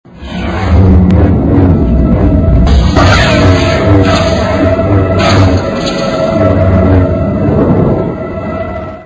Listen to the fight ...